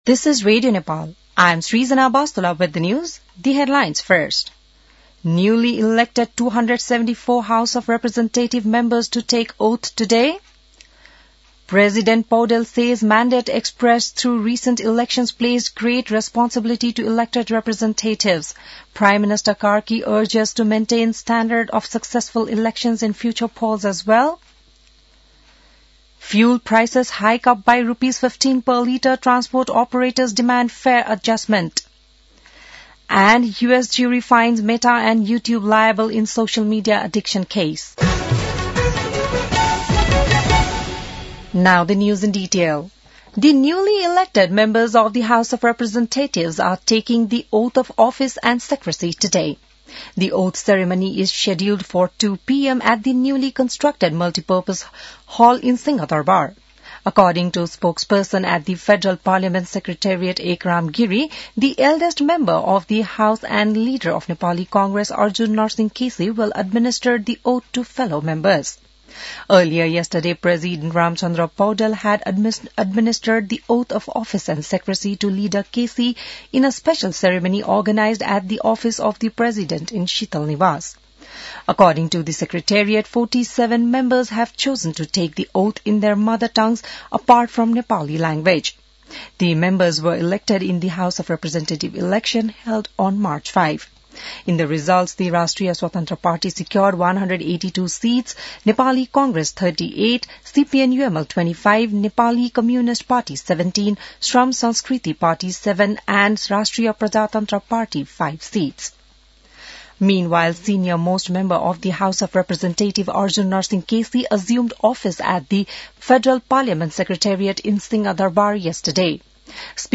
बिहान ८ बजेको अङ्ग्रेजी समाचार : १२ चैत , २०८२